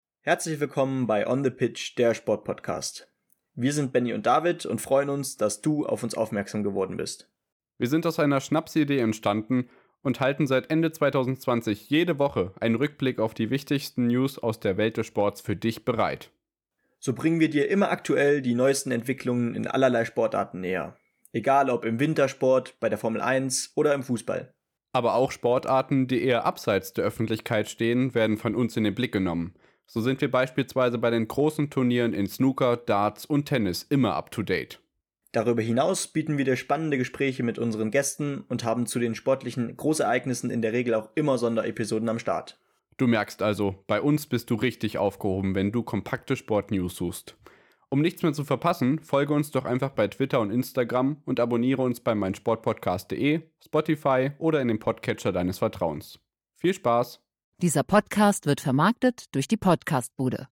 oder weniger lustig, aber immer mit ausreichend Seriosität!
Der Trailer ist ganz zu Beginn unserer Podcast-Zeit entstanden -
daher noch nicht so qualitativ hochwertig...